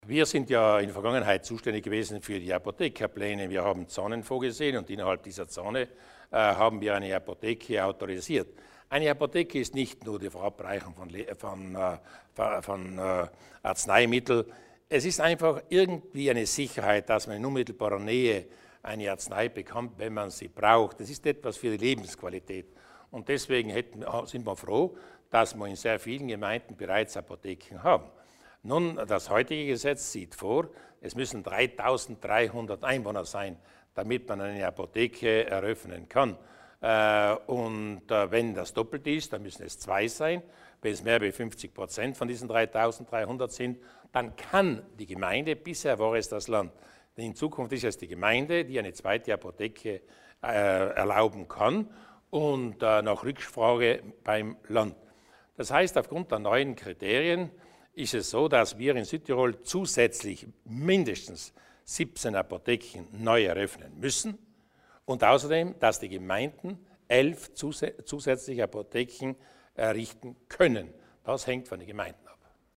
Landehauptmann Durnwalder über die Neuheiten für Apotheken in Südtirol